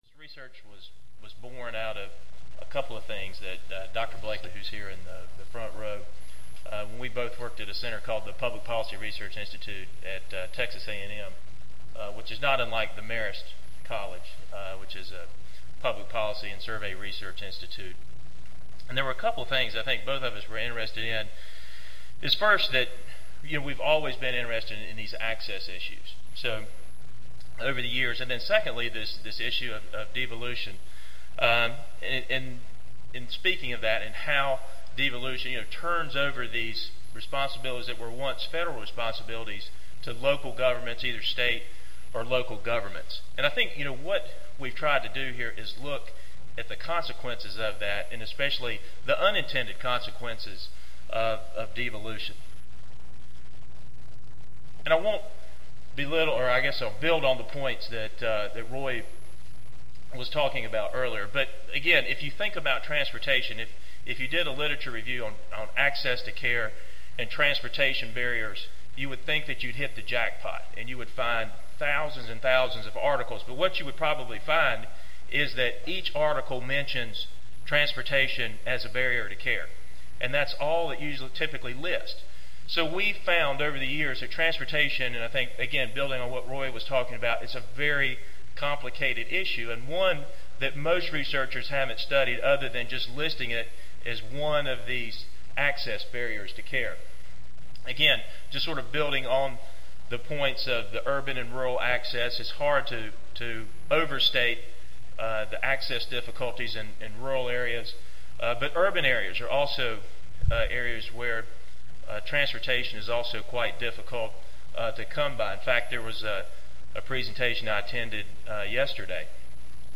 This presentation will discuss the issue of transportation as a non-economic barrier to healthcare access from several points of view.